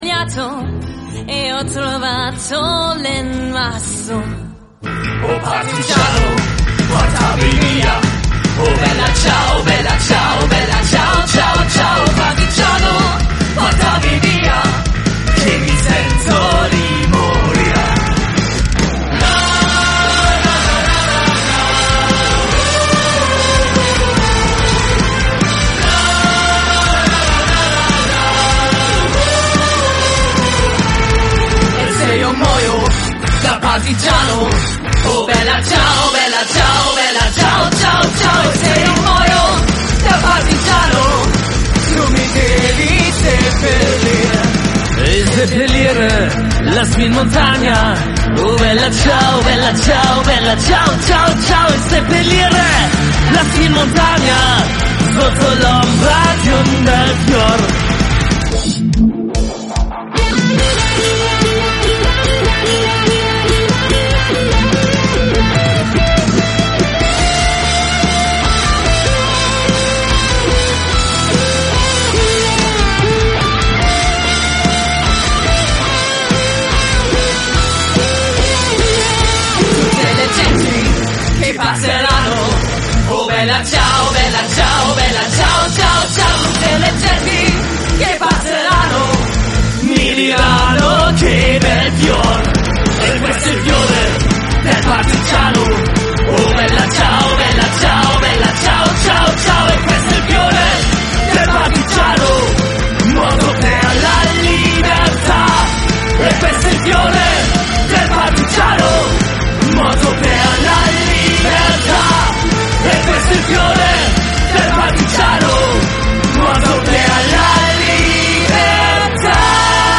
Die Weltweit erste doublefrontet Hopepunk-Band
Punkrock-Cover
natürlich in ursprünglicher italienischer Sprache.